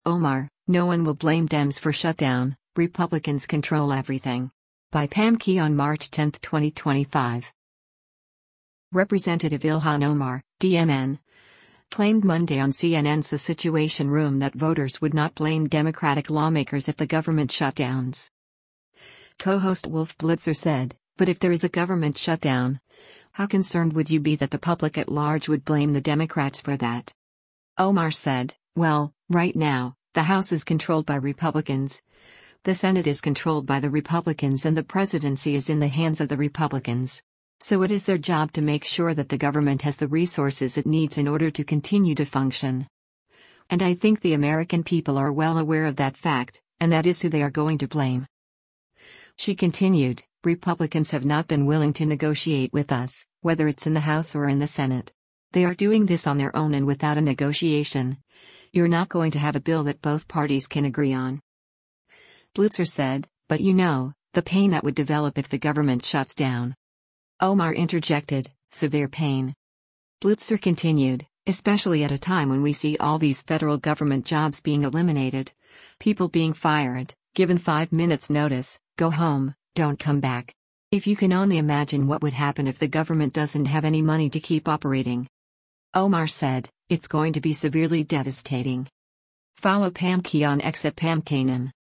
Representative Ilhan Omar (D-MN) claimed Monday on CNN’s “The Situation Room” that voters would not blame Democratic lawmakers if the government shutdowns.